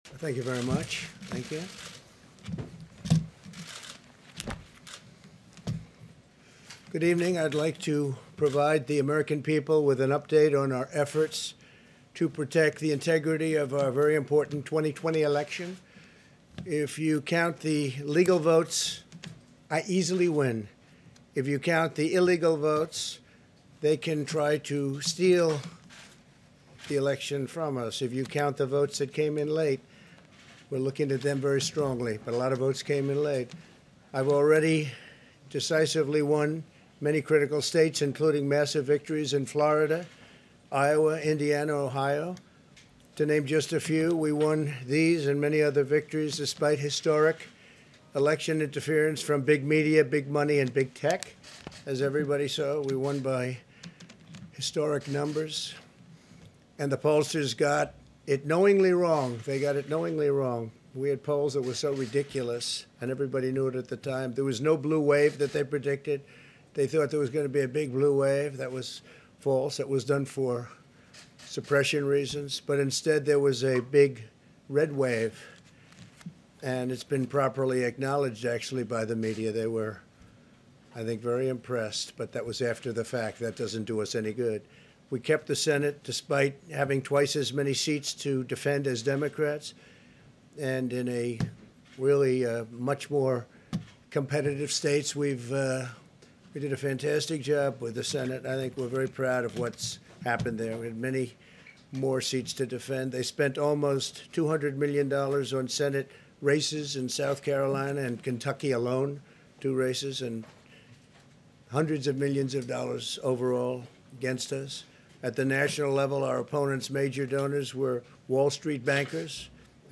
Presidential Speeches
President_Trump_Remarks_on_2020_Election.mp3